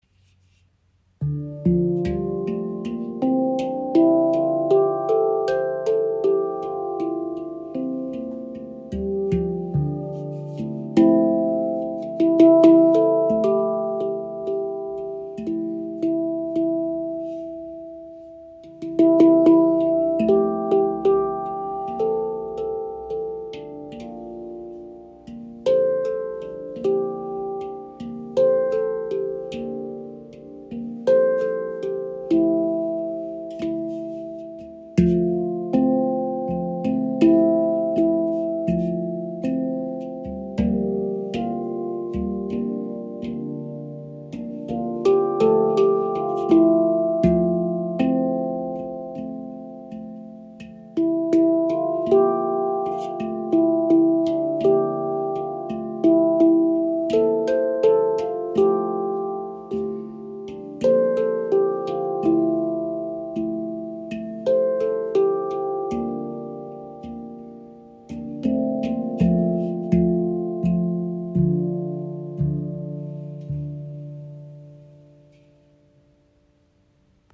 Klangbeispiel
Diese ShaktiPan aus Ember Steel zeichnet sich durch ihre besonders lange Schwingung und schöne Klangfarbe aus.